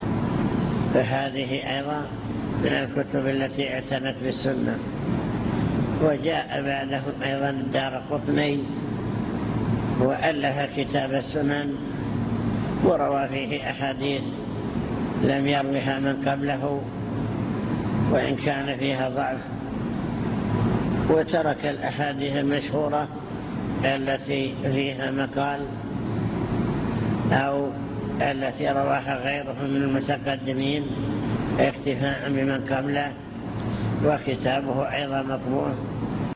المكتبة الصوتية  تسجيلات - محاضرات ودروس  محاضرات بعنوان: عناية السلف بالحديث الشريف من جاء بعد أصحاب الكتب الستة